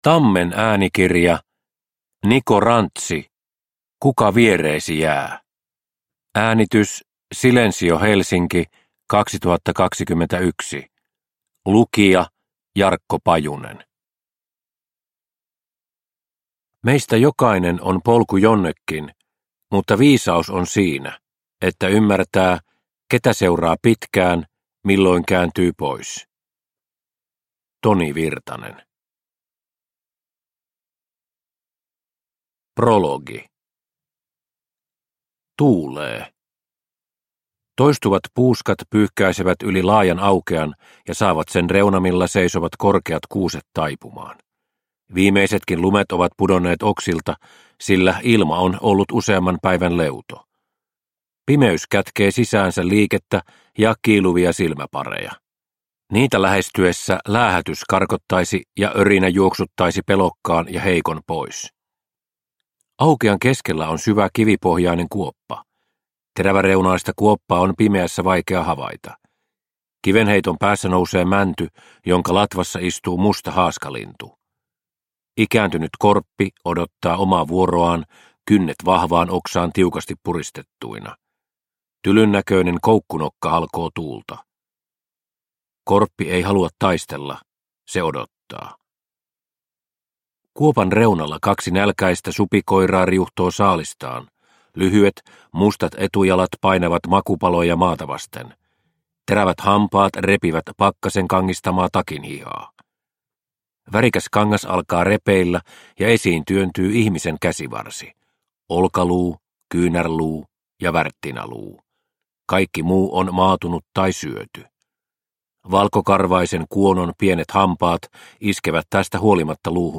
Kuka viereesi jää – Ljudbok – Laddas ner